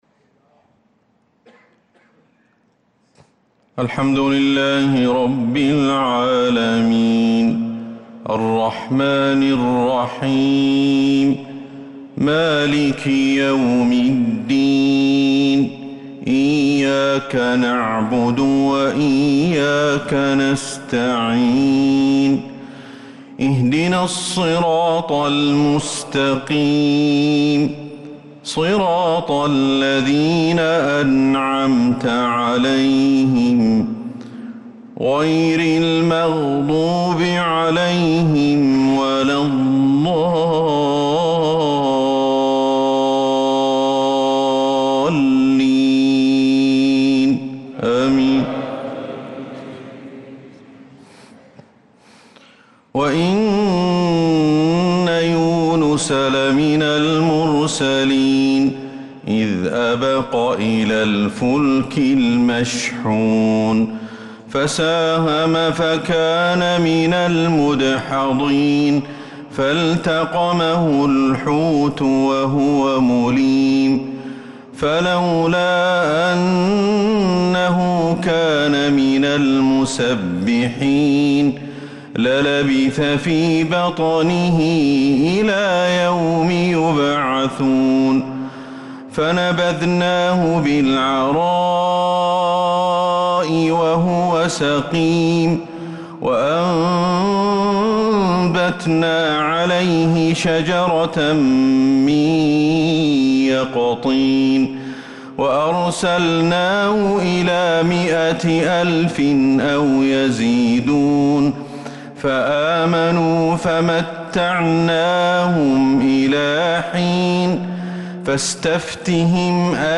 عشاء الأربعاء 8-7-1446هـ خواتيم سورة الصافات 139-182 | lsha Prayer from Surat as-Saffat 8-1-2025 > 1446 🕌 > الفروض - تلاوات الحرمين